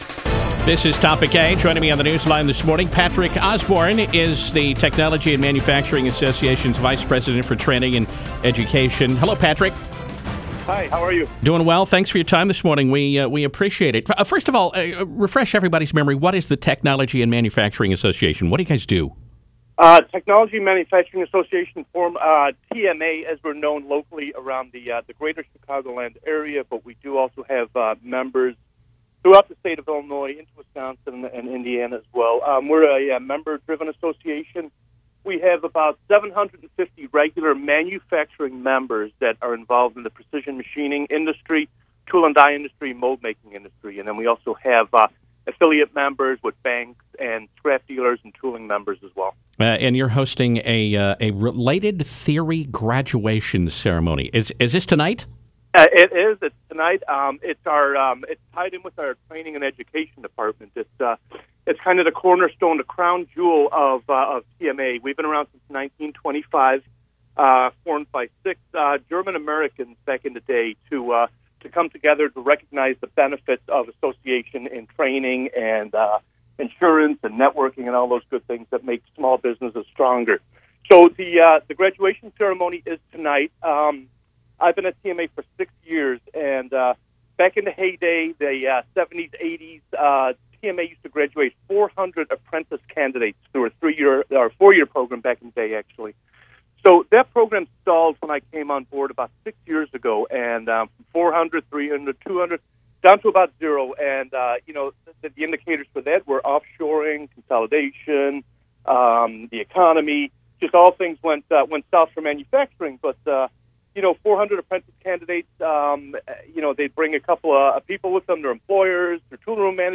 TMA on air